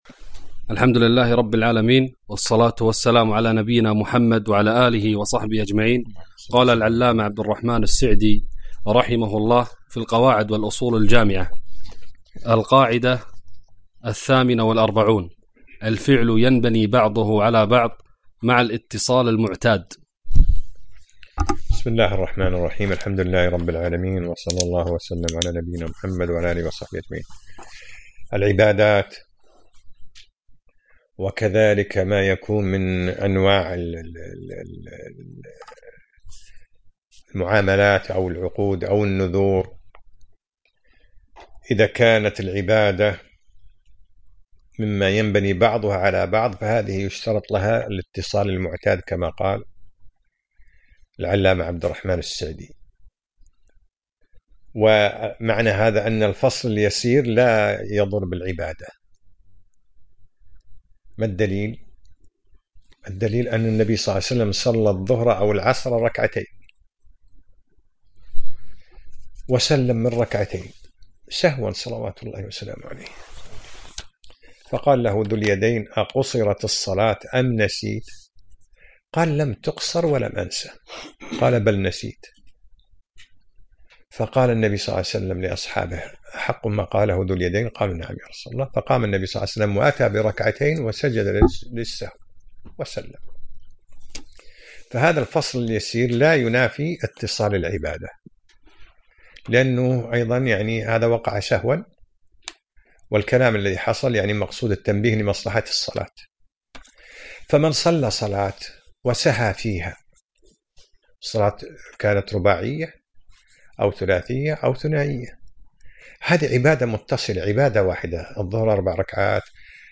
الدرس الرابع عشر : من القاعدة 45 إلى القاعدة 47 - دروس الكويت